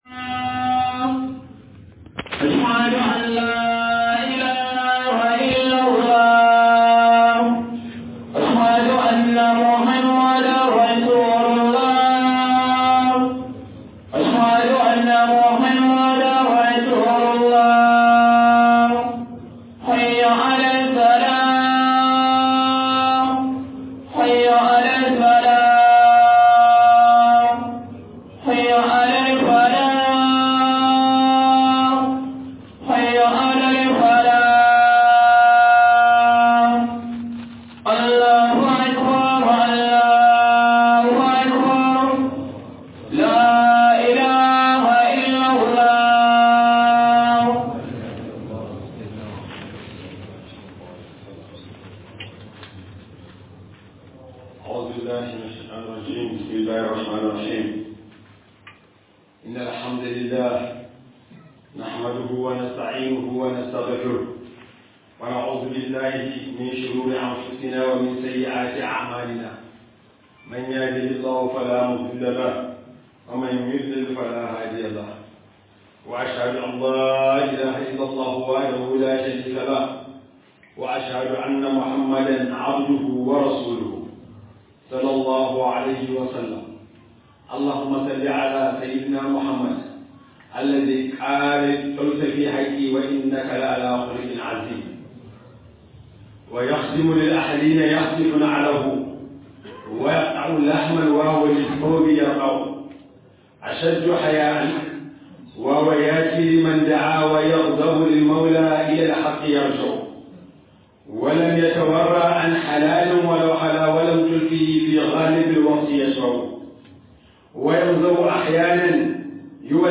Khudbah